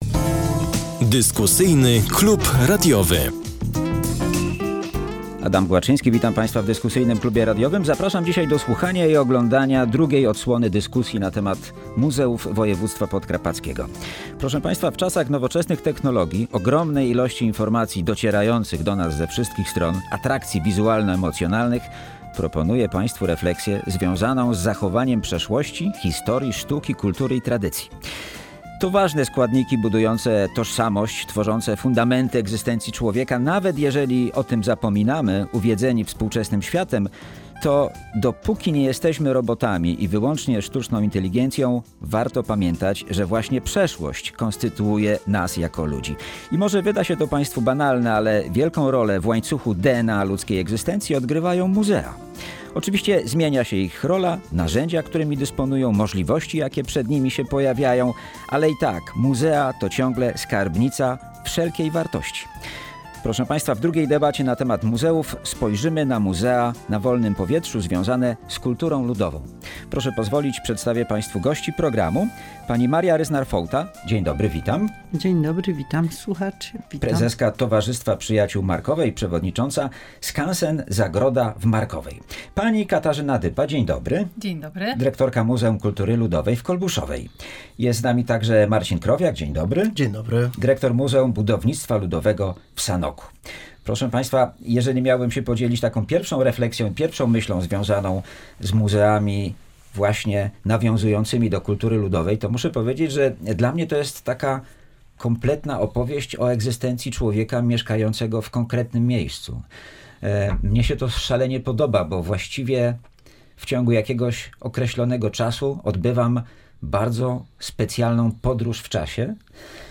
W drugiej debacie na temat muzeów spojrzymy na muzea plenerowe, muzea na wolnym powietrzu.